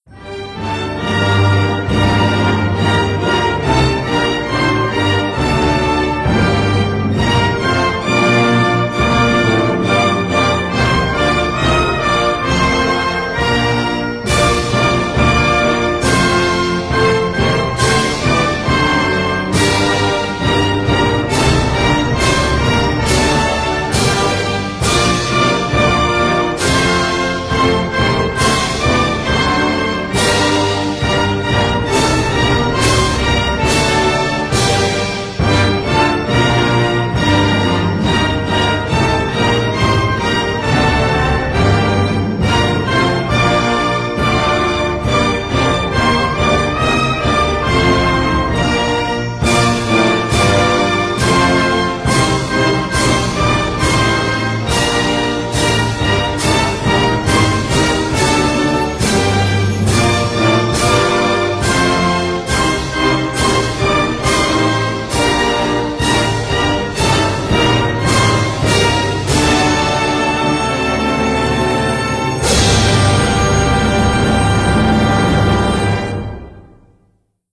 anthem_sk.wav